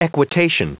Prononciation du mot equitation en anglais (fichier audio)
Prononciation du mot : equitation